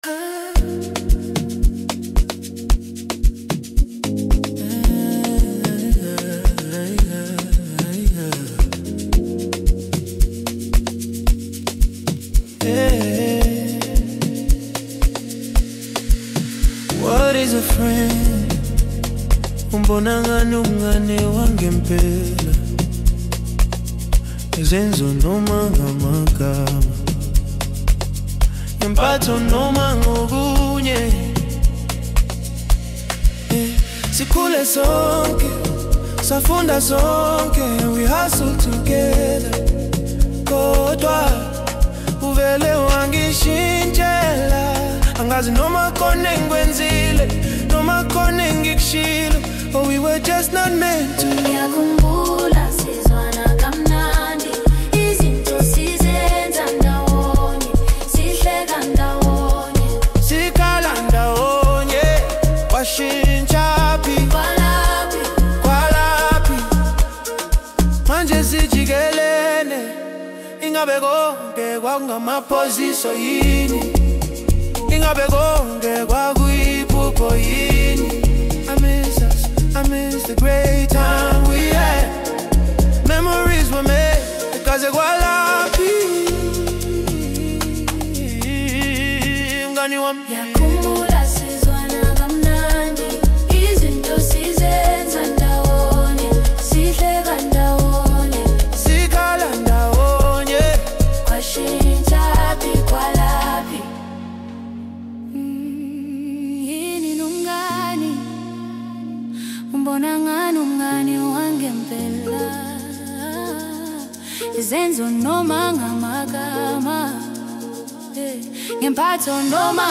Home » Amapiano
South African singer-songwriter